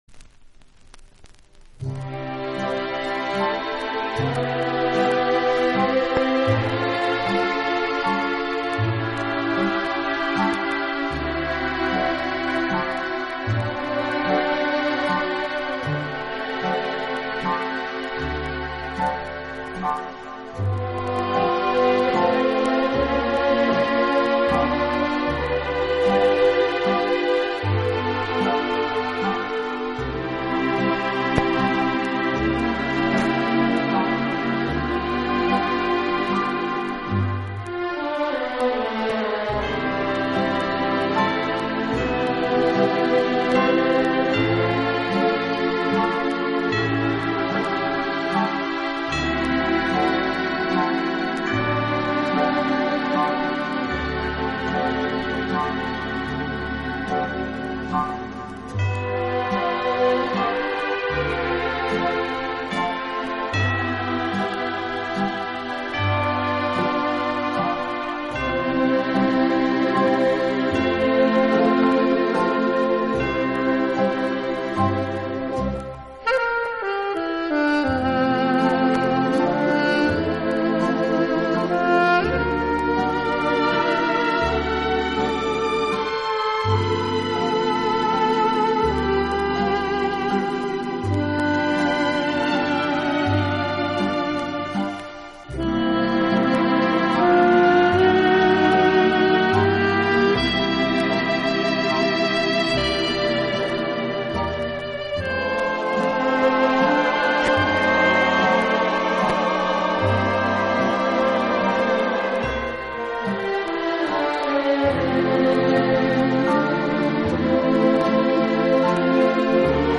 【轻音乐专辑】